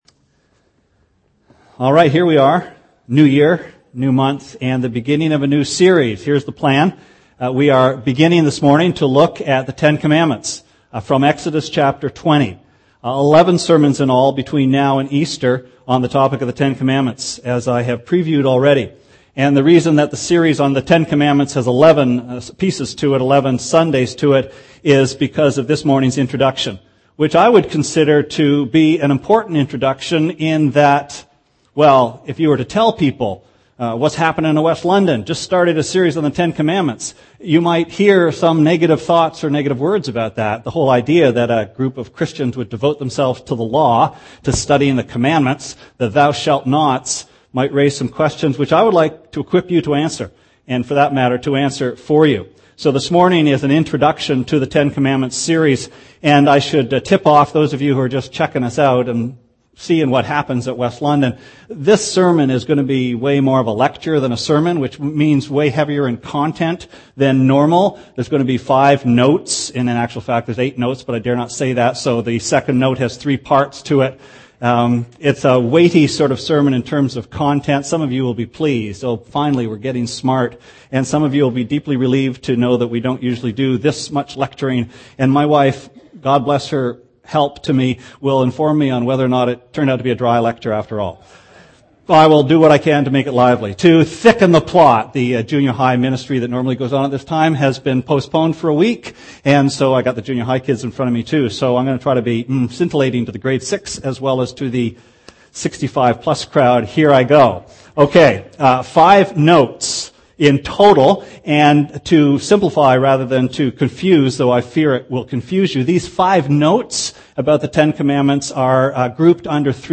Sermon Archives - West London Alliance Church
A new series of Sunday sermons on the Ten Commandments begins with a consideration of the biblical way of looking at the Law.